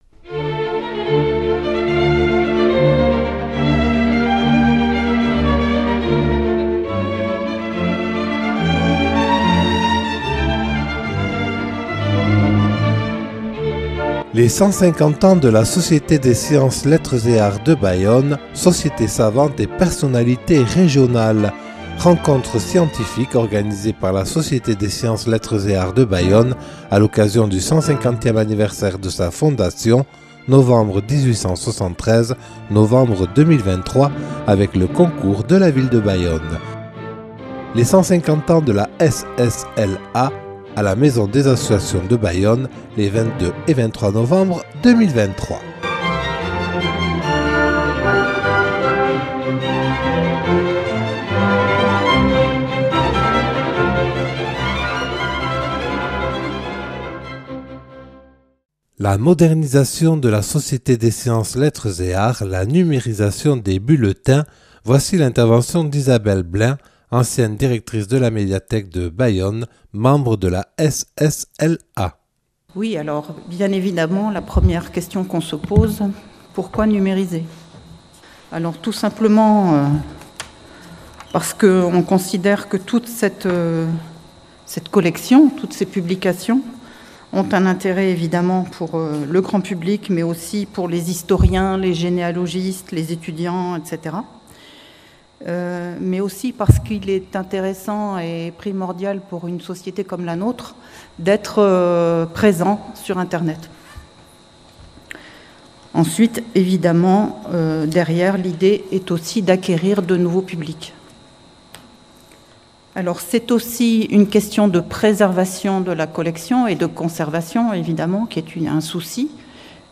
Les 150 ans de la Société des Sciences, Lettres et Arts de Bayonne – (3) – Rencontre scientifique des 22 et 23 novembre 2023